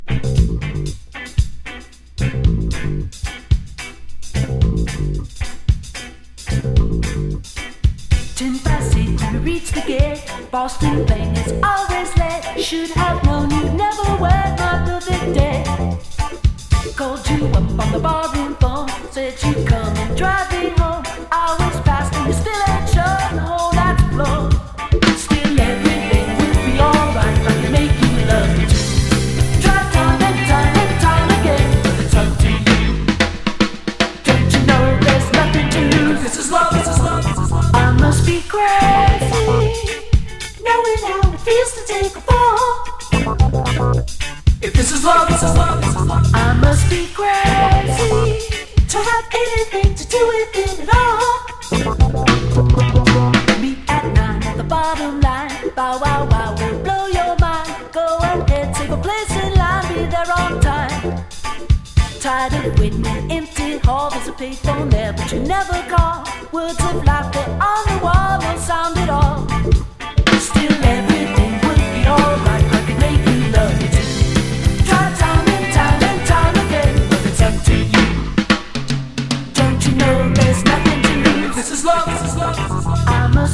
NWロッキン・レゲー